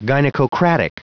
Prononciation du mot gynecocratic en anglais (fichier audio)
Prononciation du mot : gynecocratic